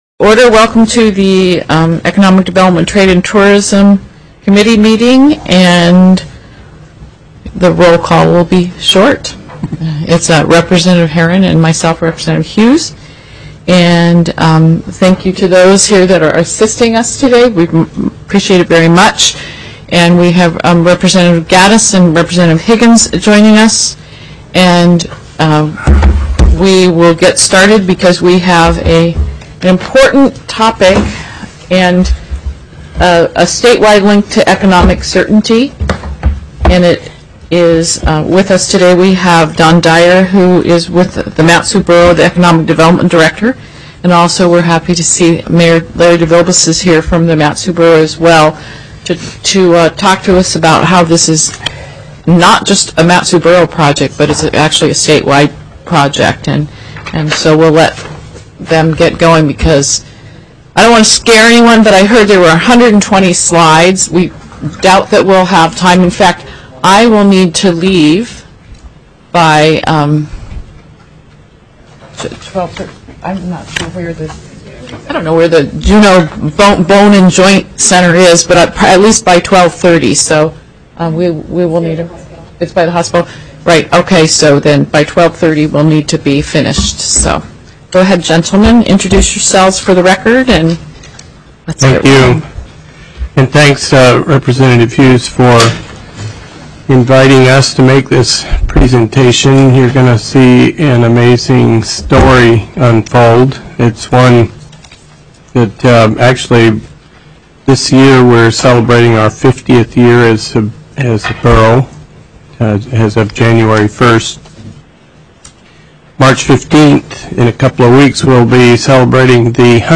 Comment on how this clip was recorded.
02/25/2014 11:15 AM House ECON. DEV., TRADE & TOURISM